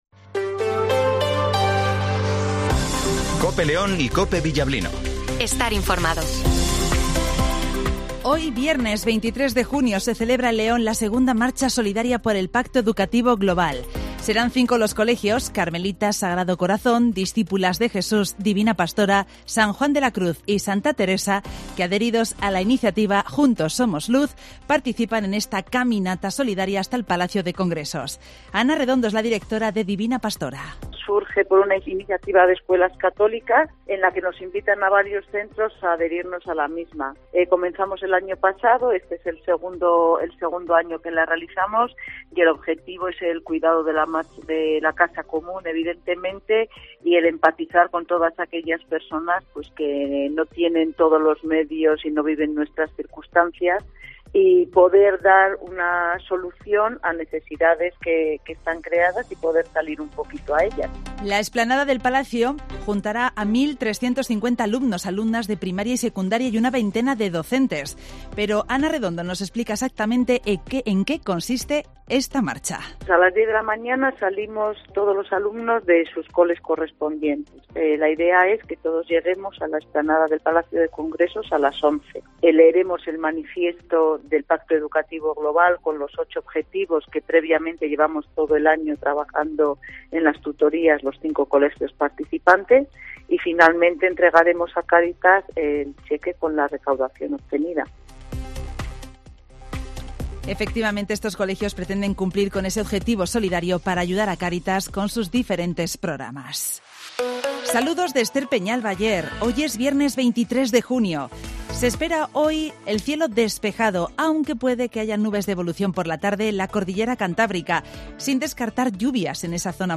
- Informatvo Matinal 08:25 h